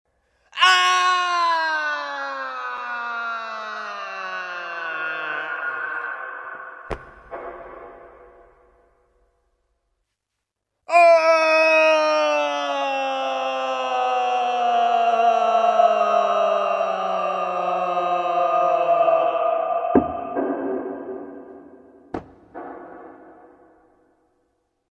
Звуки колодца
Звук падения тела в глубокий колодец под аккомпанемент крика